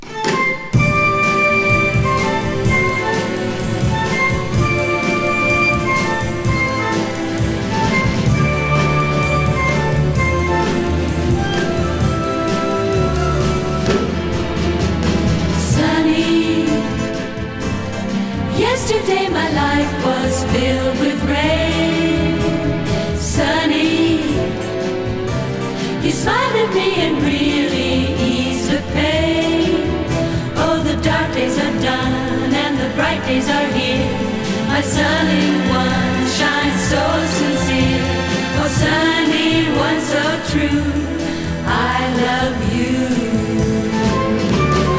Jazz, Pop, Vocal, Easy Listening　USA　12inchレコード　33rpm　Stereo